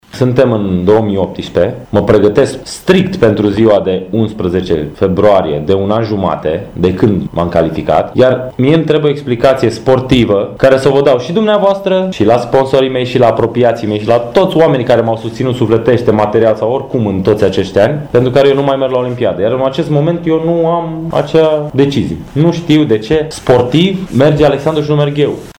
cu prilejul unei conferințe de presă